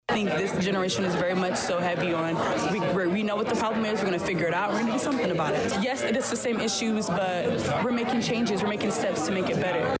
(ABOVE) Congresswoman Robin Kelly leads a roundtable discussion with Danville High School students focused on gun violence.